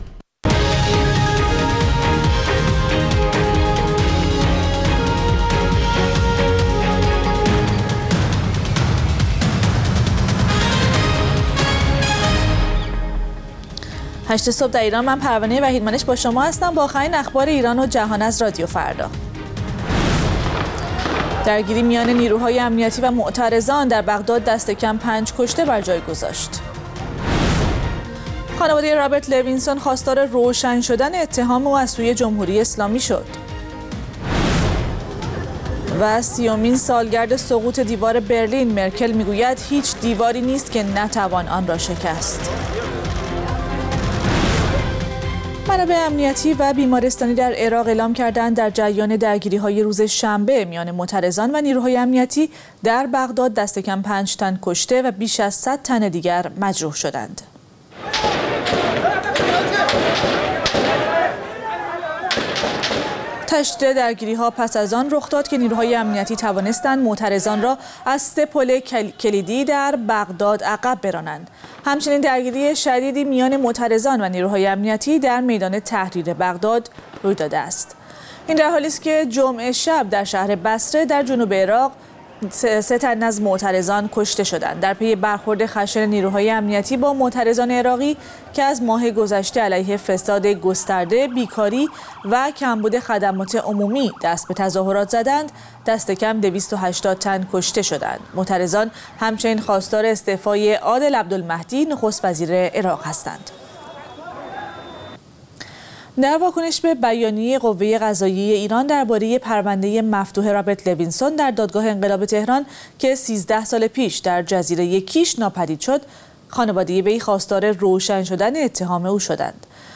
اخبار رادیو فردا، ساعت ۸:۰۰